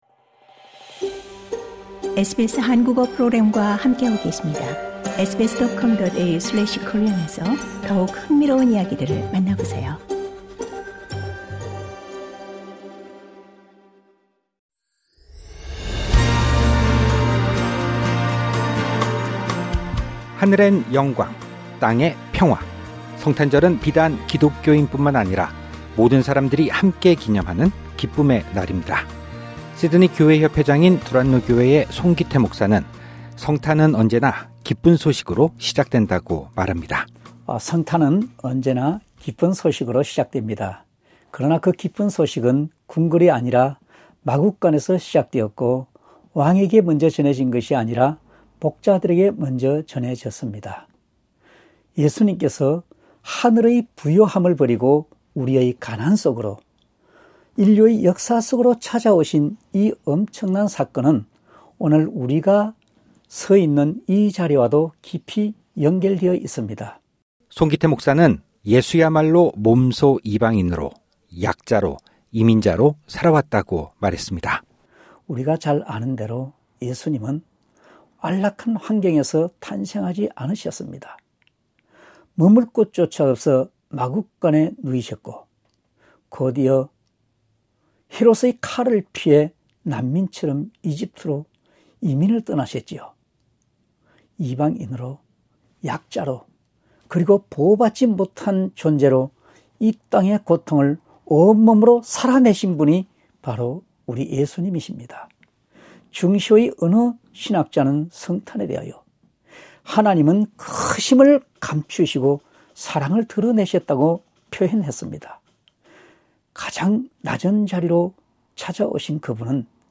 해마다 오는 성탄도 그런 의미가 아닐까 하는 생각을 한다”고 말합니다. 2025년 성탄절을 맞아 진행된 특집 인터뷰를 들으시려면 상단의 팟캐스트를 클릭하세요.